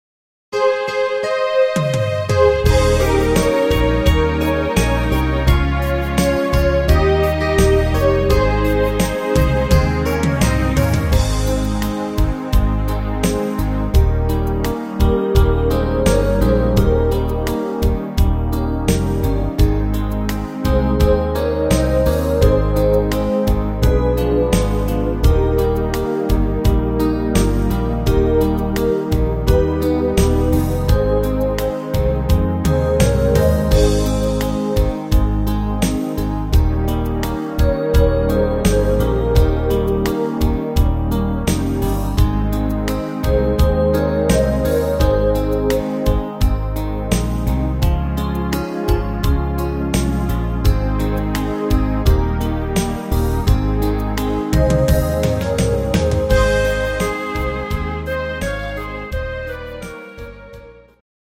instr. Klarinette